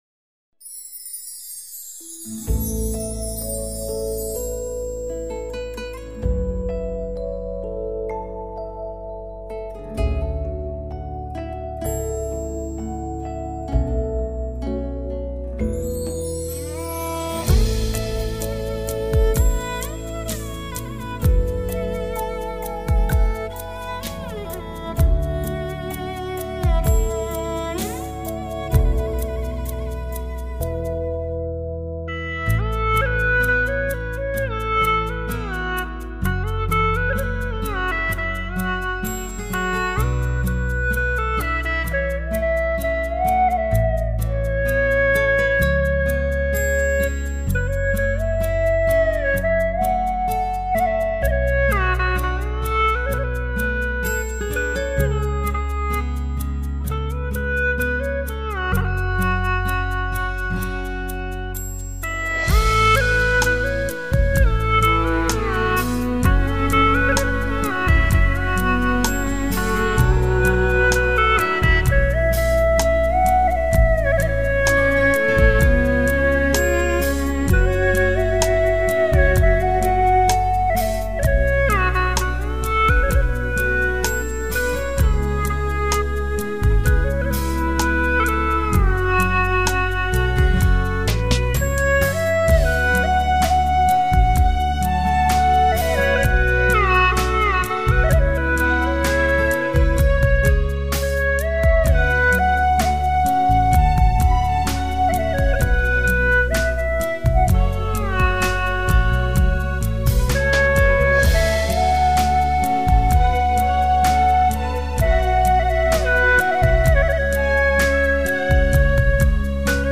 调式 : A 曲类 : 流行